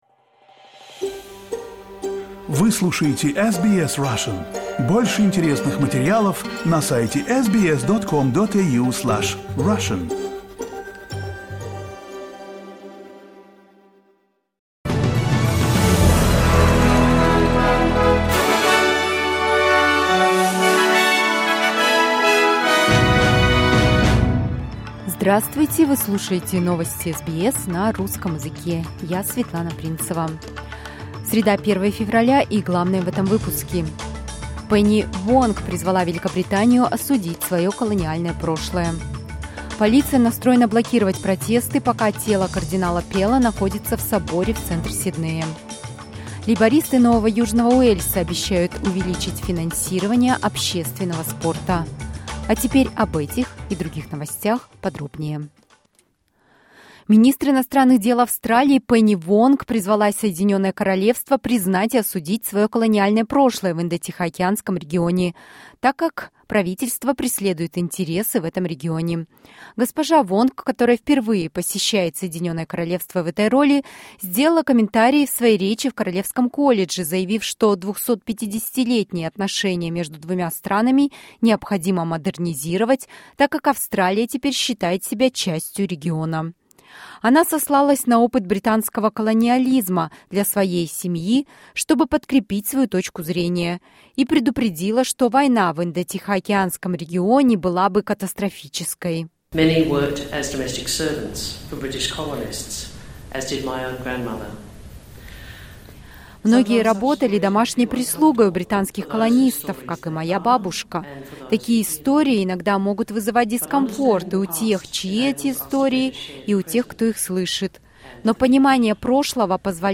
SBS news in Russian — 01.02.2023